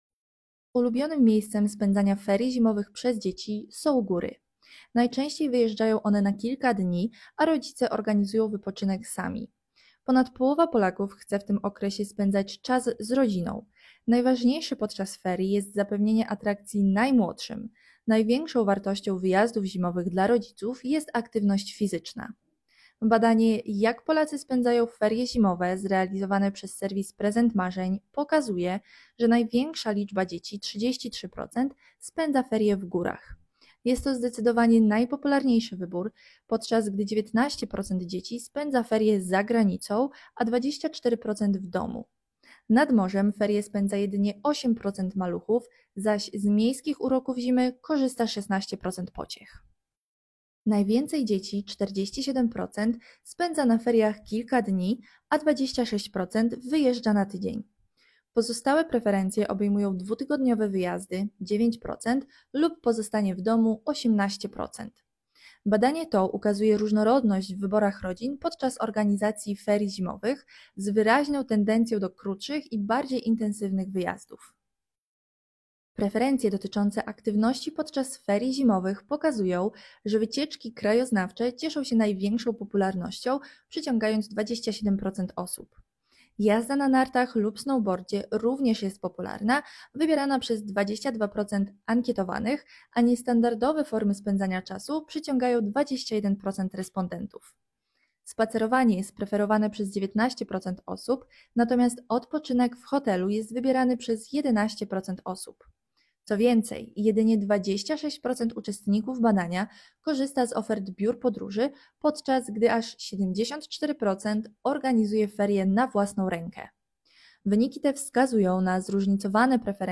sonda_ferie_.m4a